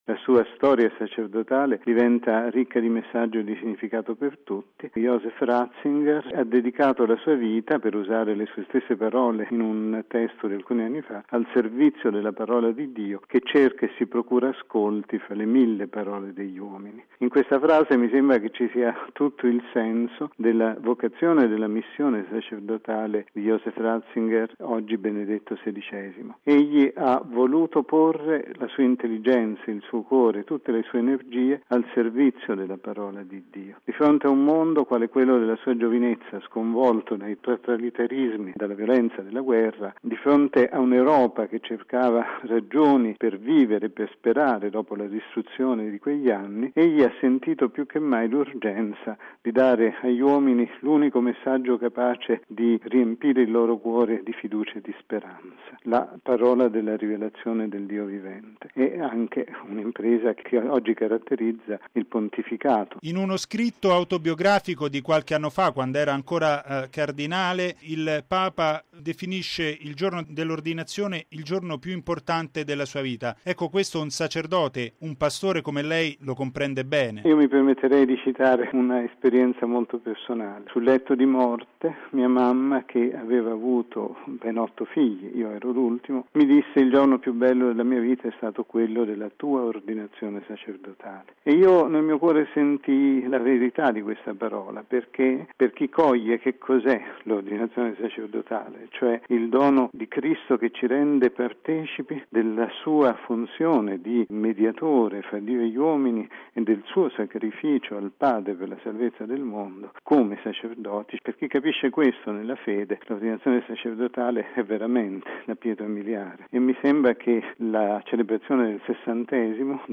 Messa in Vaticano del cardinale Comastri, intervista con l’arcivescovo Forte